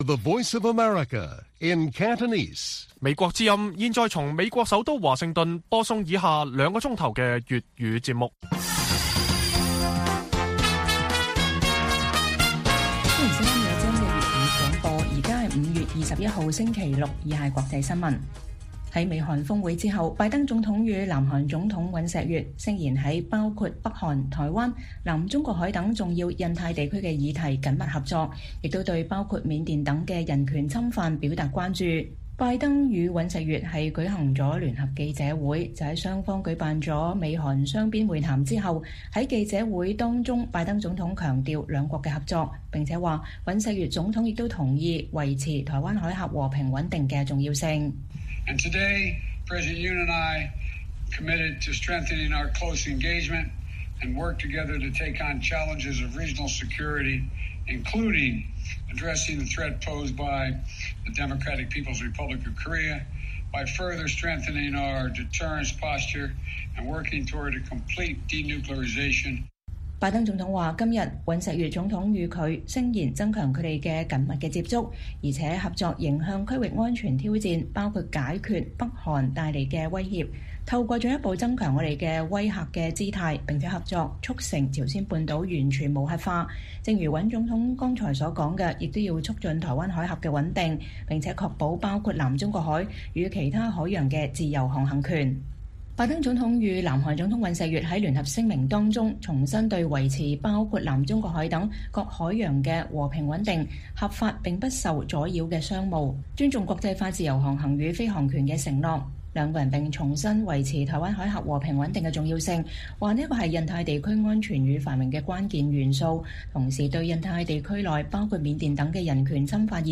粵語新聞 晚上9-10點：美韓領導人誓言在北韓、台灣、南中國海等議題緊密合作